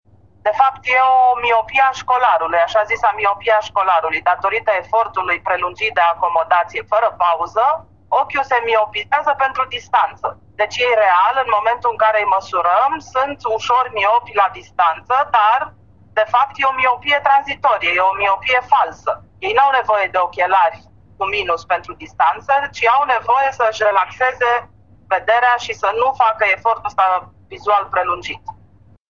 medic de specialitate